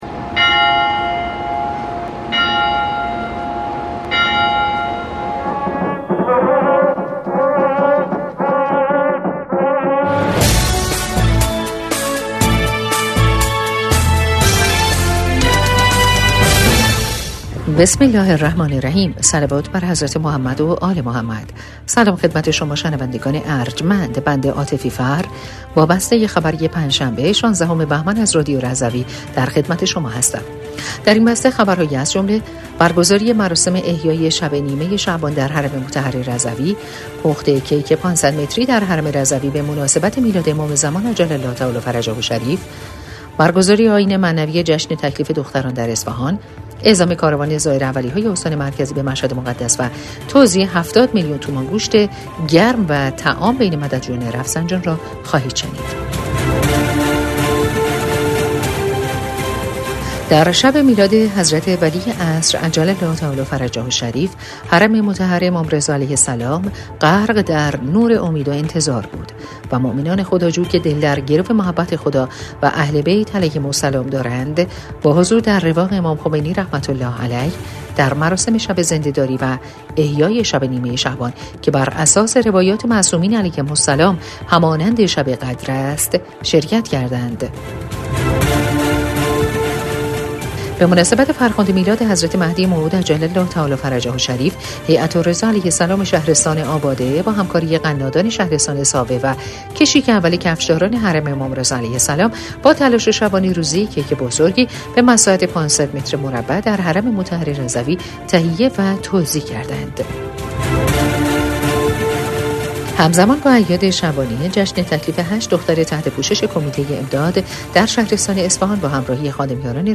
بسته خبری ۱۶ بهمن ۱۴۰۴ رادیو رضوی؛